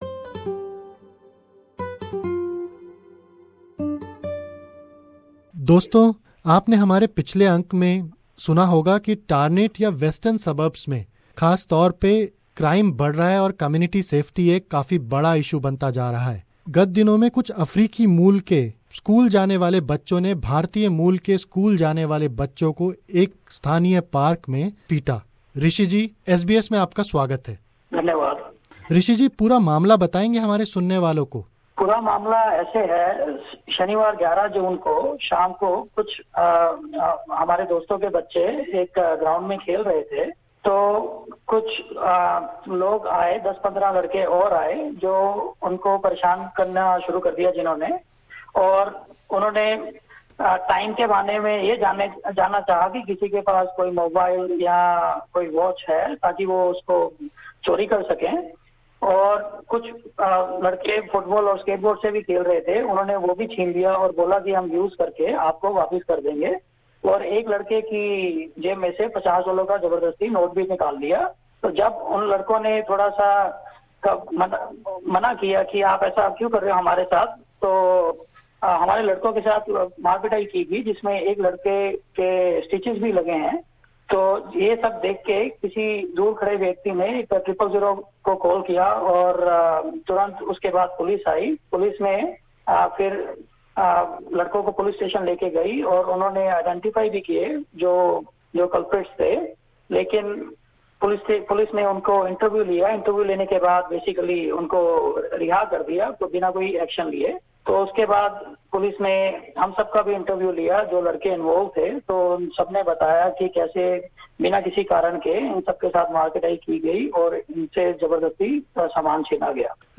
SBS Hindi View Podcast Series